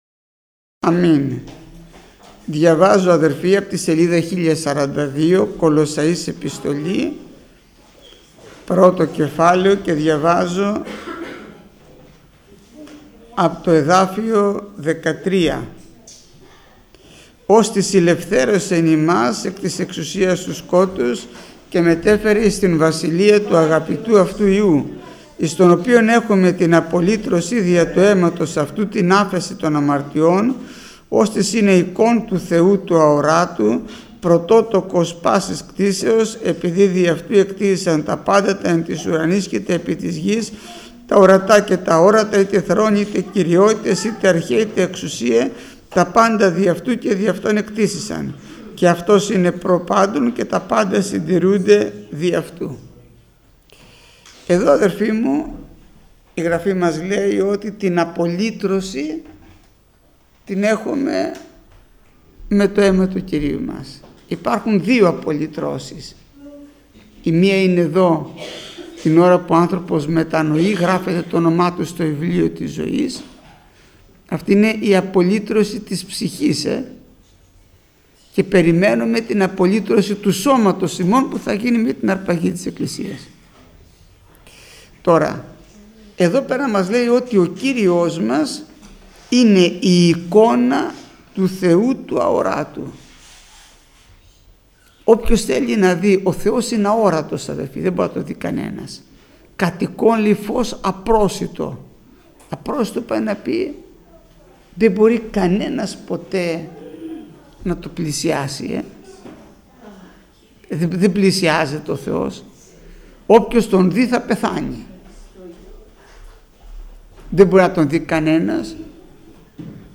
Μηνύματα Θείας Κοινωνίας